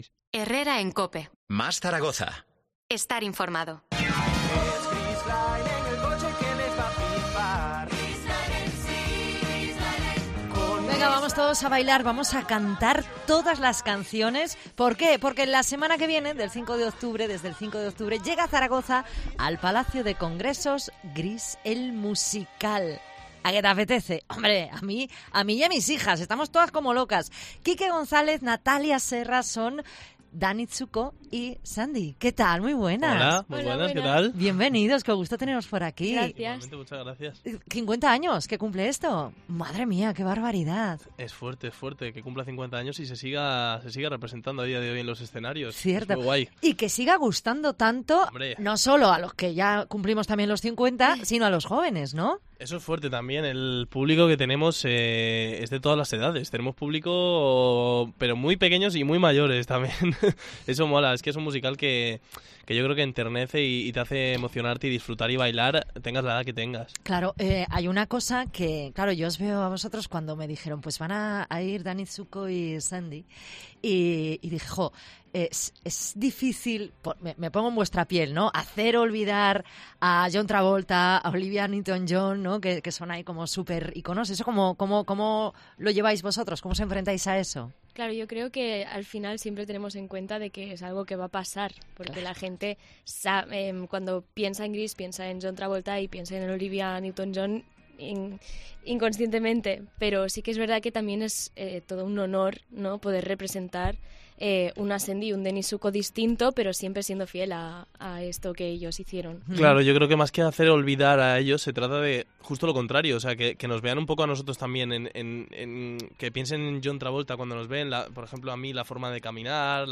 Entrevista GREASE EL MUSICAL